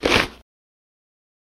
Leather Strap Cinch